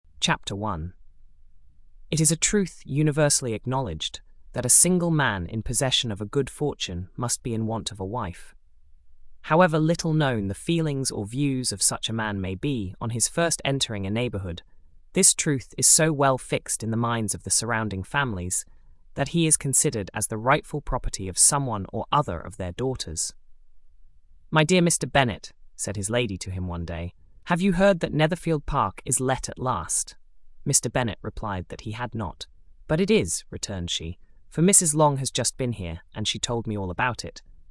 We selected the Neural voice for the three following services: Microsoft Azure, Google Gemini, Amazon Polly while selecting the standard voices for Eleven Labs’ and OpenAI.
• OpenAI Sample:
OpenAI_clip.mp3